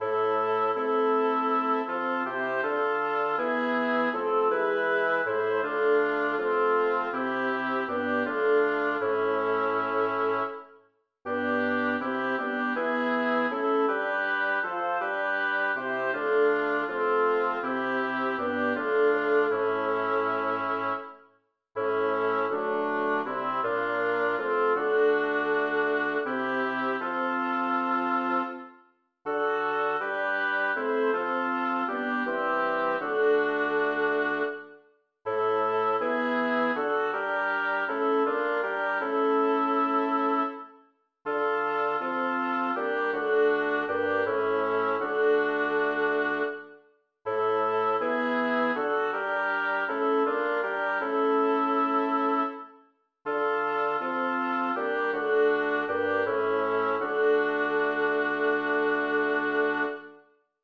Title: Rosina, wo war dein Gestalt Composer: Jacob Meiland Lyricist: Number of voices: 4vv Voicing: SATB Genre: Secular, Lied
Language: German Instruments: A cappella